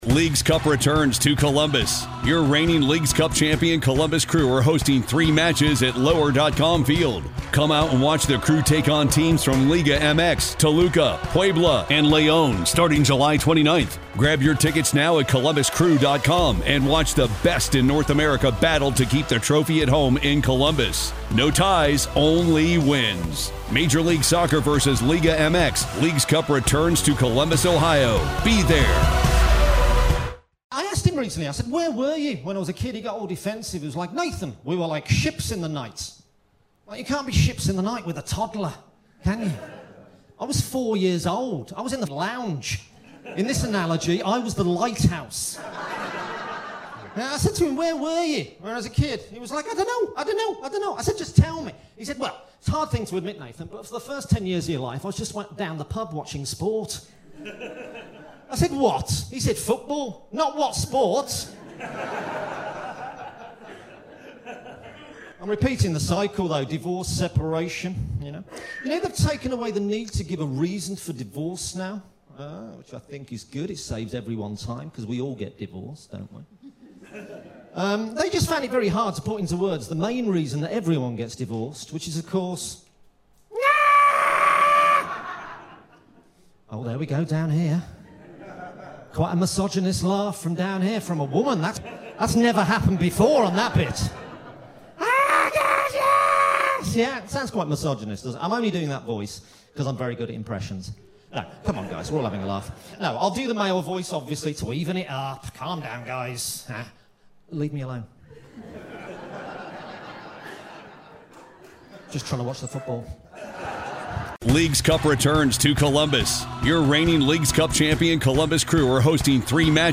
Recorded Live at Edinburgh Fringe.